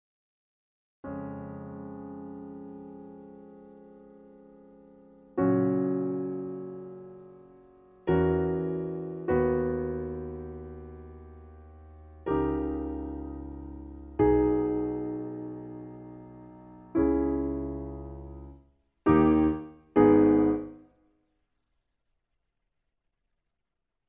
In G sharp. Piano Accompaniment